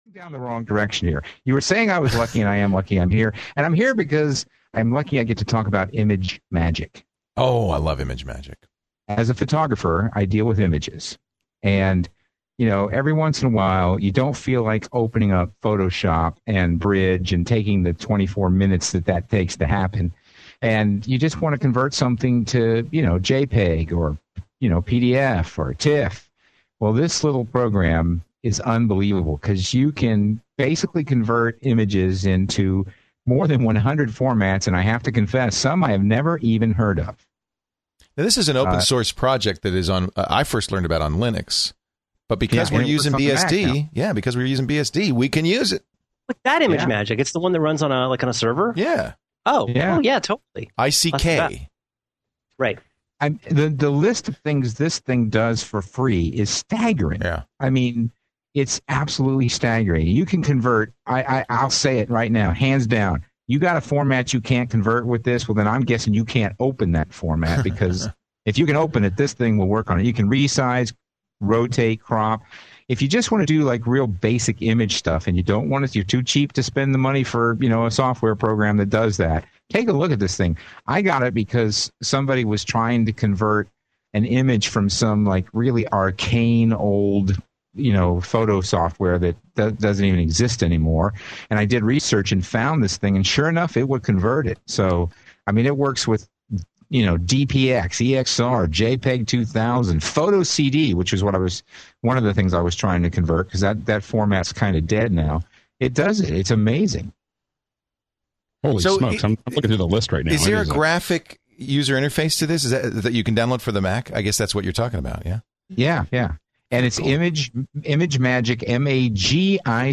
As they do every week, they went around the round table asking each contributor for their "Pick of the Week".
piece of the discussion as an MP3 here so you don't have to listen to the whole hour and 20 minute long show.